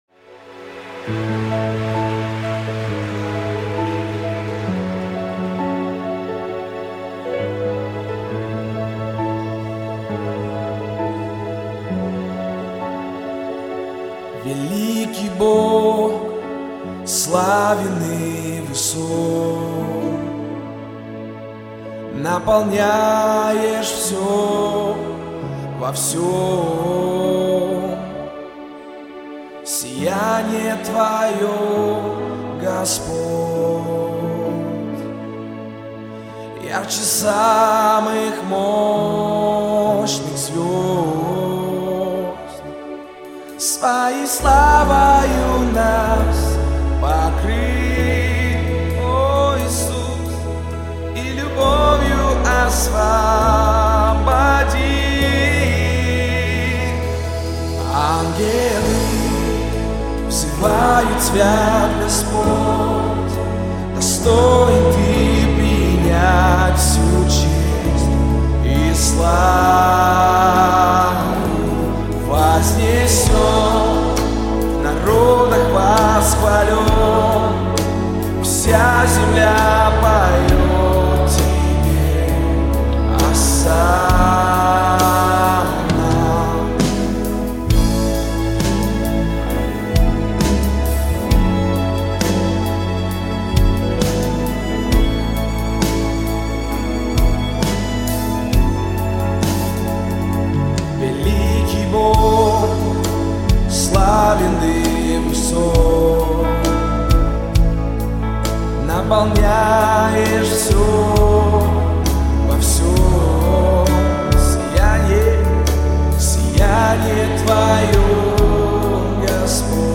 32592 просмотра 15442 прослушивания 1151 скачиваний BPM: 133